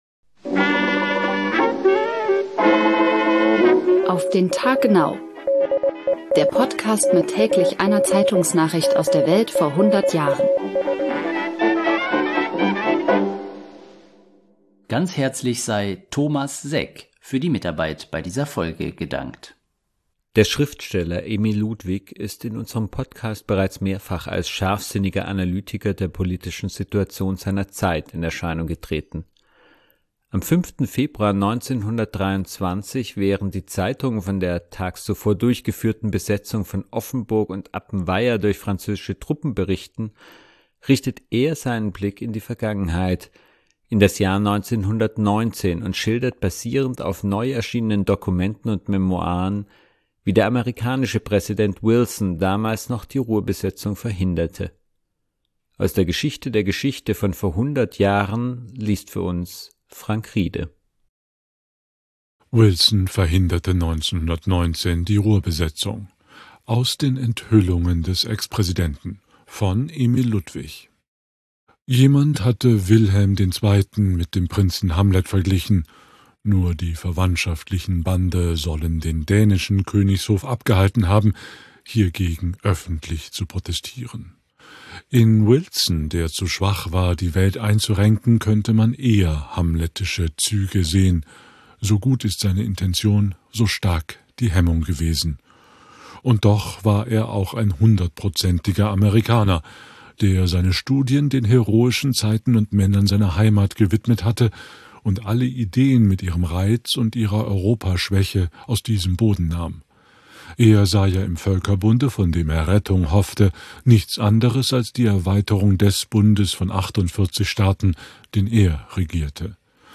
Am 5. Februar 1923, während die Zeitungen von der tags zuvor durchgeführten Besetzung von Offenburg und Appenweier durch französische Truppen berichten, richtet er seinen Blick in die Vergangenheit, in das Jahr 1919 und schildert, basierend auf neu erschienen Dokumenten und Memoiren, wie der amerikanische Präsident Wilson damals noch die Ruhrbesetzung verhinderte. Aus der Geschichte der Geschichte von vor hundert Jahren liest für uns